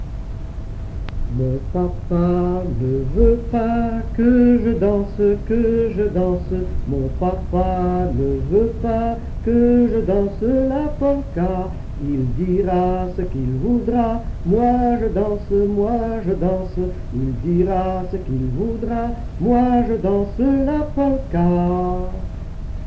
Genre : chant
Type : ronde, chanson à danser
Lieu d'enregistrement : Seraing
Support : bande magnétique
Ronde.